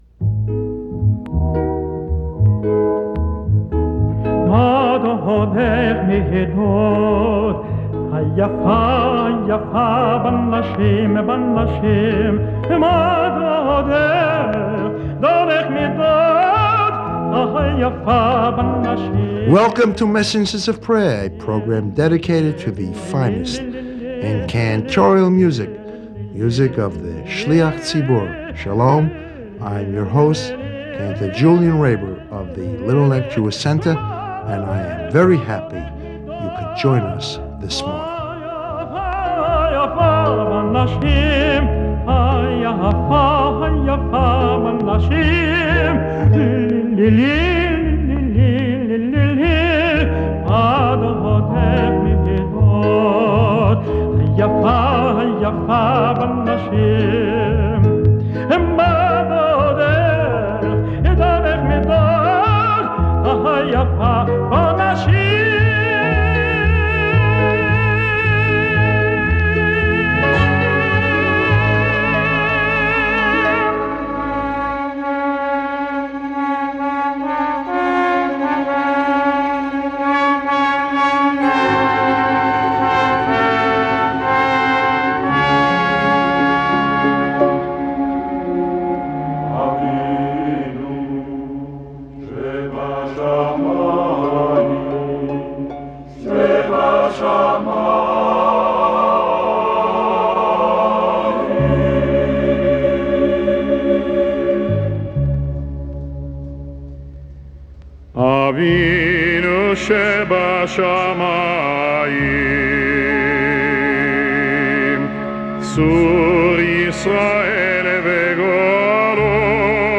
As originally broadcast on WEVD on 5/29/1983